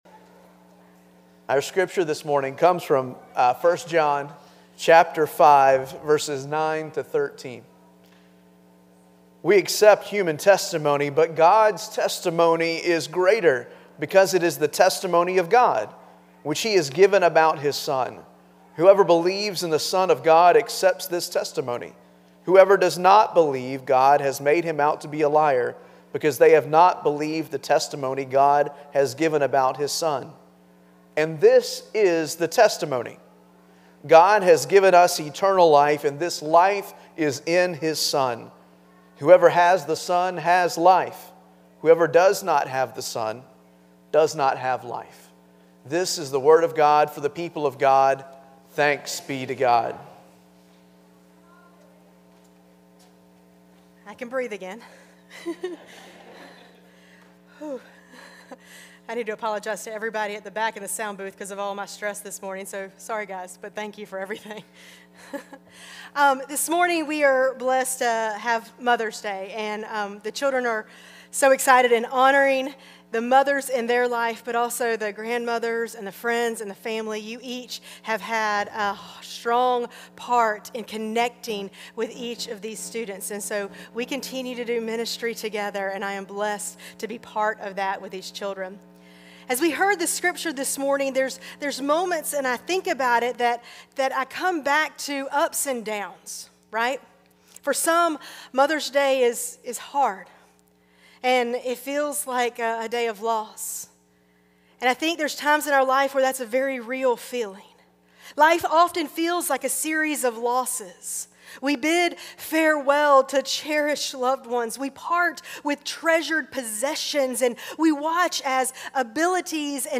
Passage: 1 John 5: 9-13 Service Type: Traditional Service